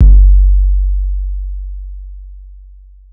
Waka KICK Edited (71).wav